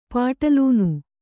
♪ pāṭalūnu